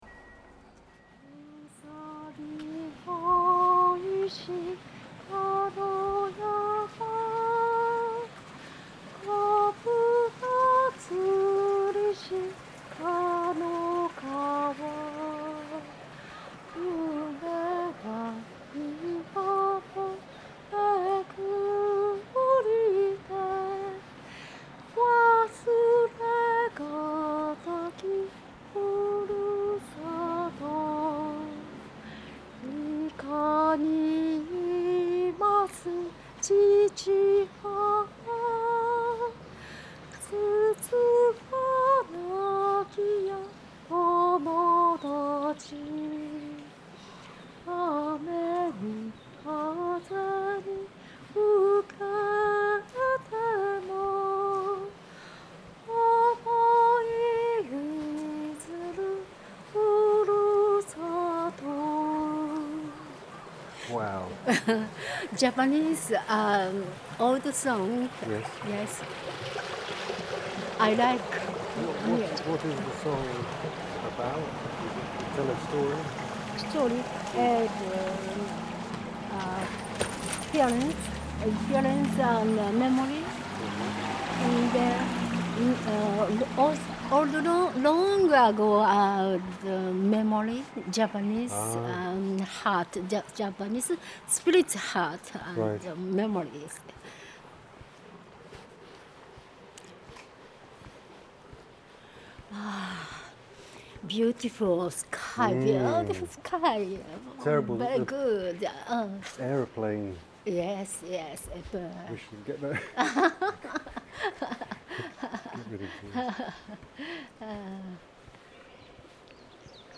In “Some Memories of Bamboo”, these unprocessed recordings have been stitched together to evoke the heard life of Kami-Katsura, the district’s textures offered more colour through the short stories that accompany each track in the CD booklet.
I caught the softest suggestion of sad singing in the air and hurriedly connected the microphone to the recorder and settled the headphones over my ears.
As we strolled together, she sang and talked, we ate boiled sweets and I understood her to have suffered grievously in love.
Field Recording Series by Gruenrekorder